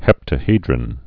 (hĕptə-hēdrən)